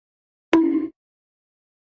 bonk sound effect 2
bonk-sound-effect-2